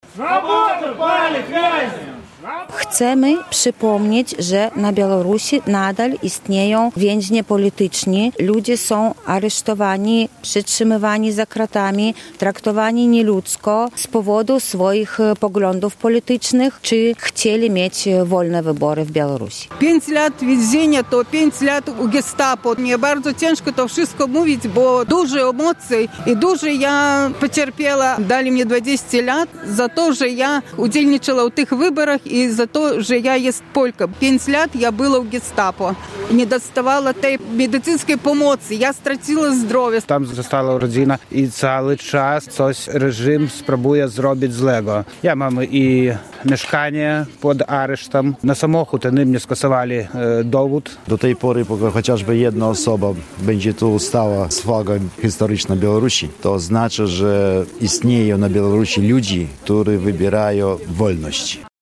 Jesteśmy głosem białoruskich więźniów politycznych! – mówili uczestnicy niedzielnej (7.12) akcji solidarności przed budynkiem Konsulatu Białoruskiego w Białymstoku.